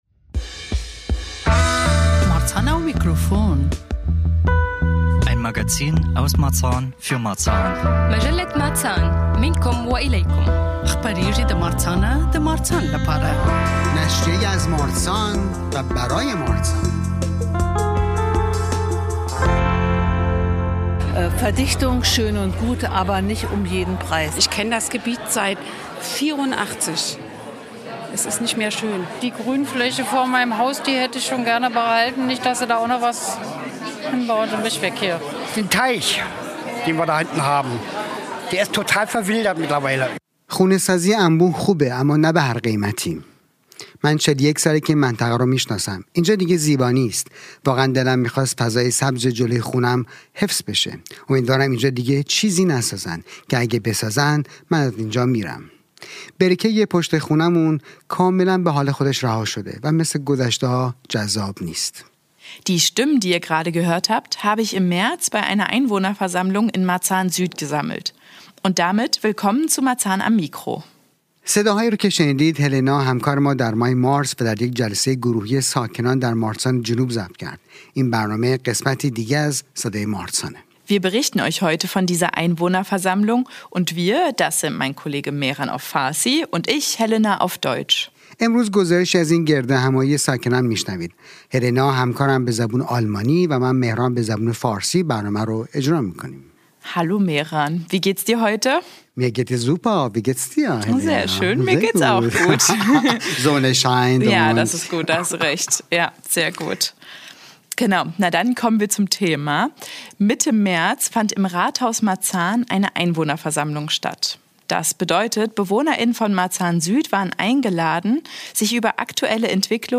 Die Bezirksstadträt*innen Nadja Zivkovic (Bezirksbürgermeisterin, Grünflächenamt), Stefan Bley (Schule und Sport) und Heike Wessoly (Stadtentwickung) stellten sich den Fragen der Bürger*innen. Wir waren vor Ort und haben uns umgehört: Warum sind die Leute gekommen, welche Fragen haben sie und was erhoffen sie sich vom Gespräch. Ein Thema wurde besonders heißt diskutiert: die geplanten Neubauten auf dem Helene-Weigel-Platz.